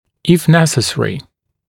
[ɪf ‘nesəsərɪ][иф ‘нэсэсэри]если необходимо, в случае необходимости